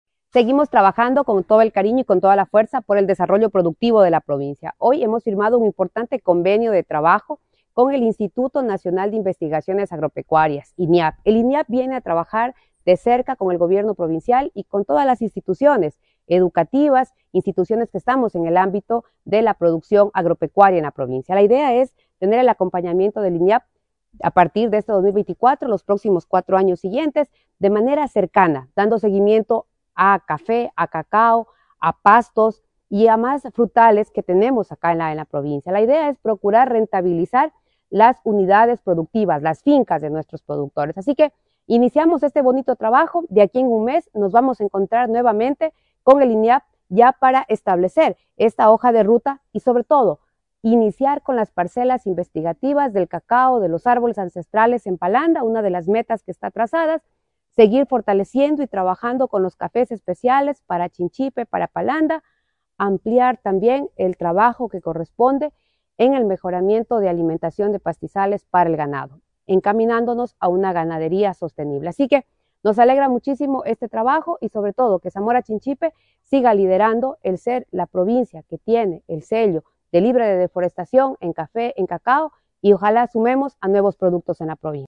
KARLA REÁTEGUI, PREFECTA